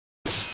metal.mp3